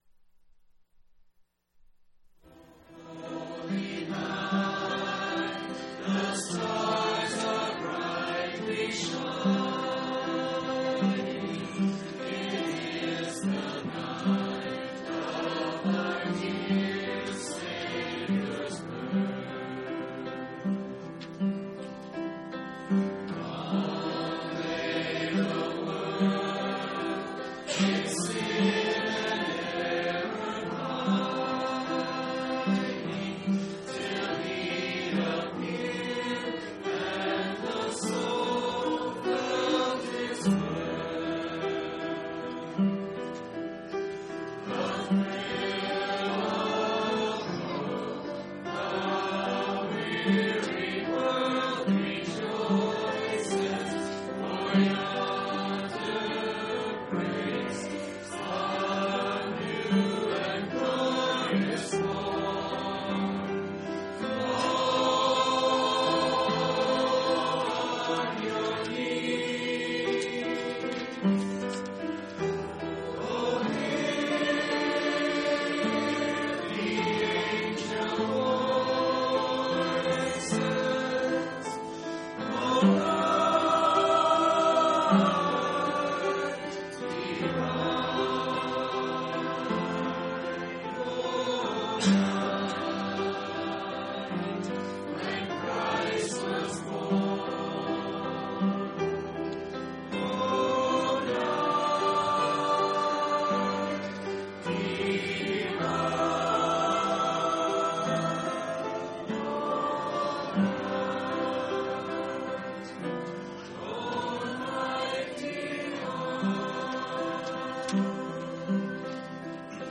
12/29/2002 Location: Phoenix Local Event